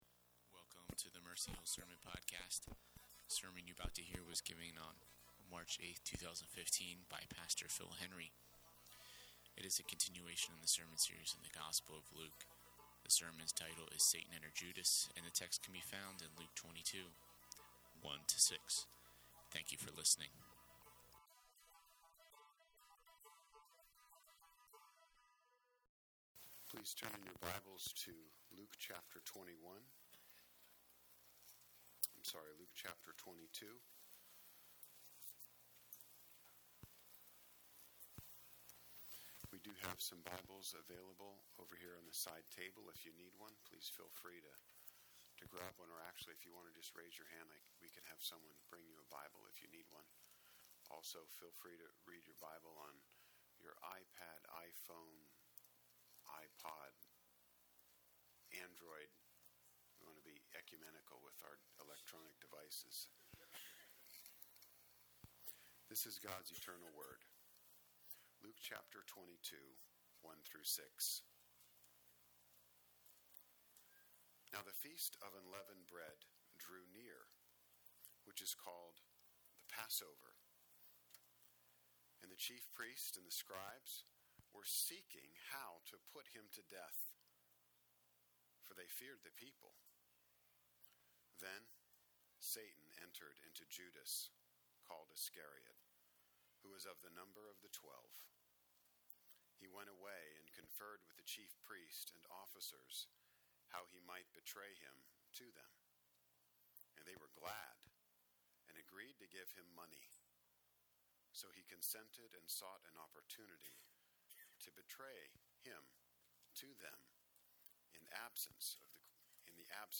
Satan Entered Judas - Mercy Hill Presbyterian Sermons - Mercy Hill NJ